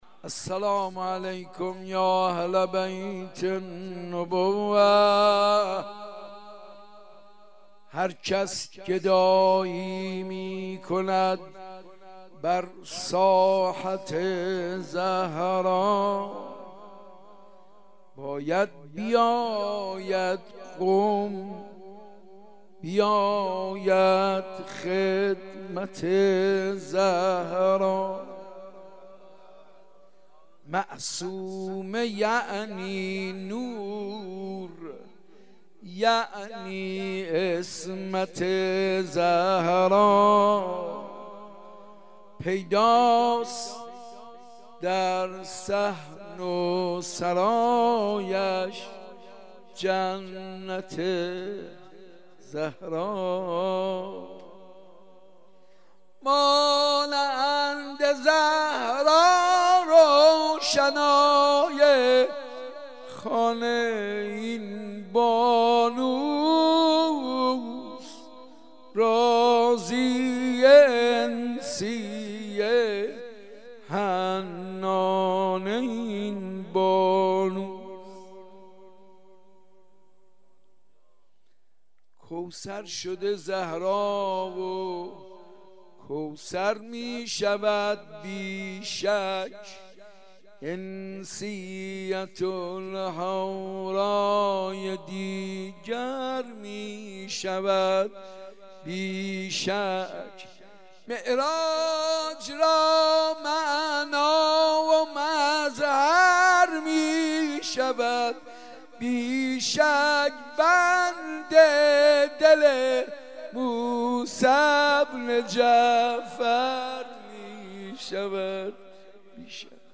حاج منصور ارضی/مراسم شب ولادت حضرت معصومه(س)